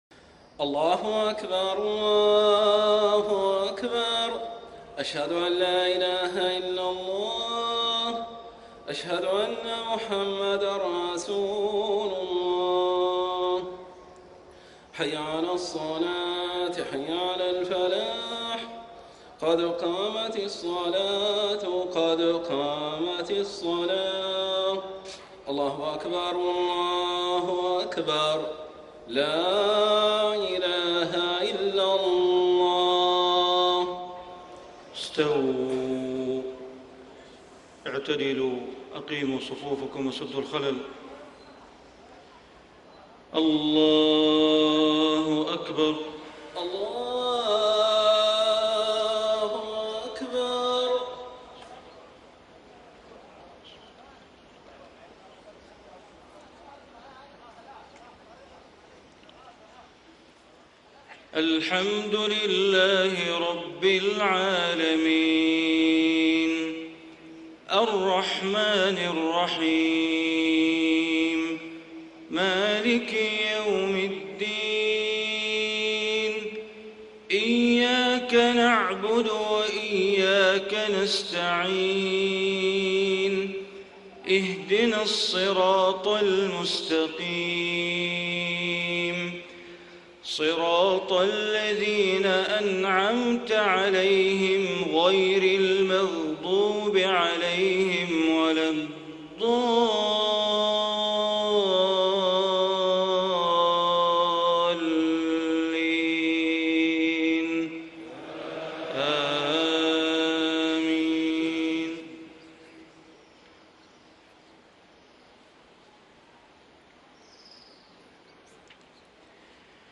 صلاة المغرب 4-2-1435 من سورة الحديد > 1435 🕋 > الفروض - تلاوات الحرمين